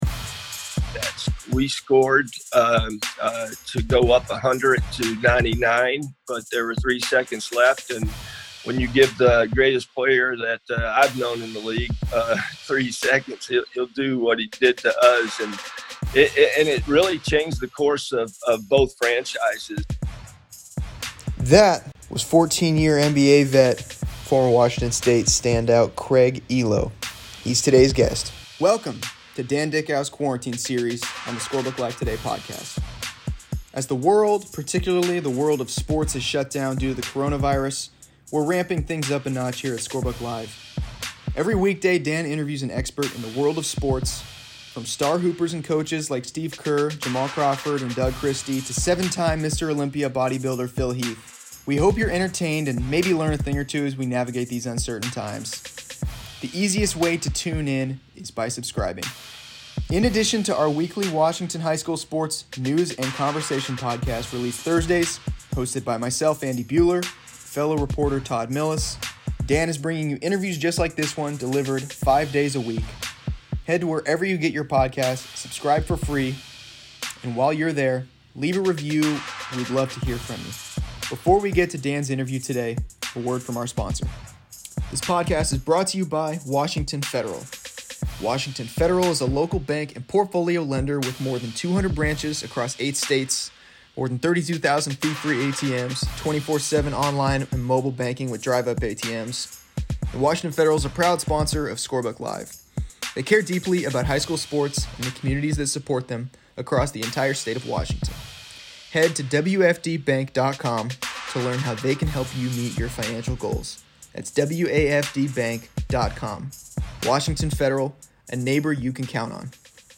Each weekday, Dickau releases an interview with a wide range of experts in the world of sports. On Friday, former NBA player and Washington State standout Craig Ehlo joins. Ehlo was a 14-year NBA vet who played seven seasons with the Cleveland Cavaliers.